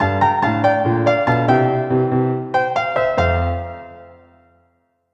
サウンドロゴ